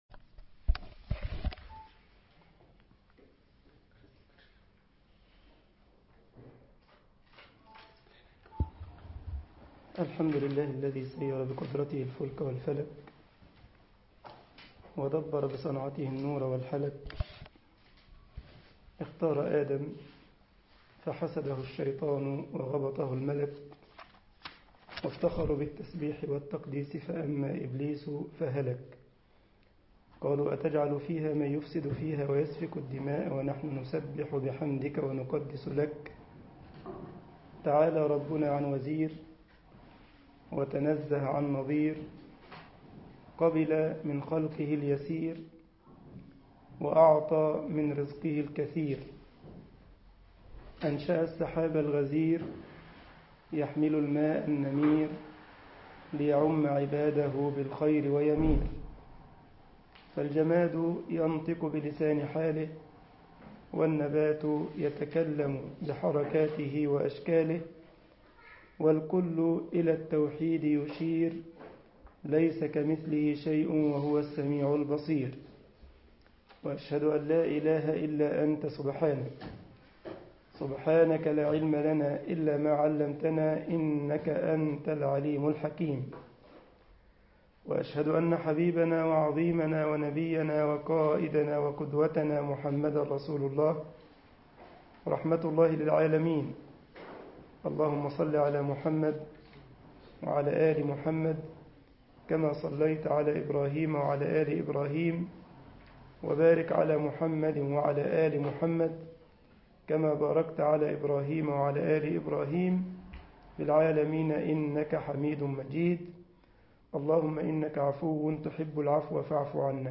مسجد الجمعية الإسلامية بالسارلند ـ ألمانيا درس